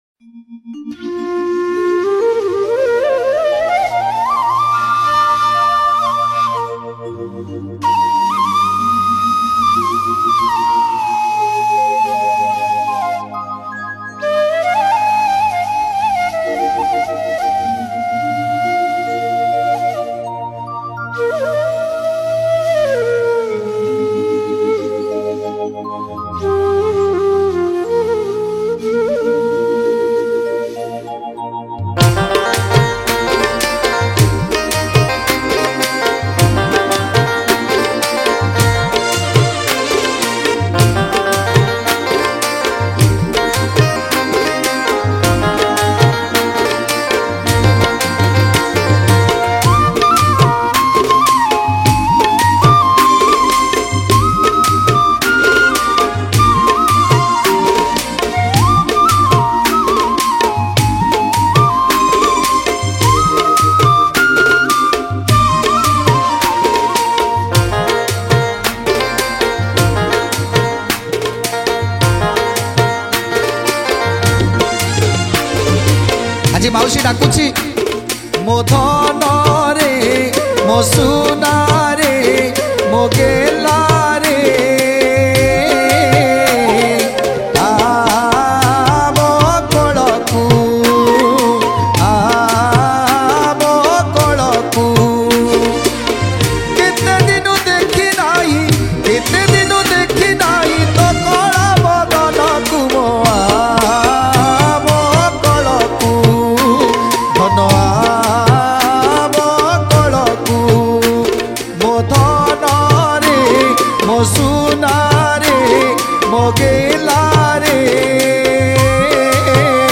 Ratha Yatra Odia Bhajan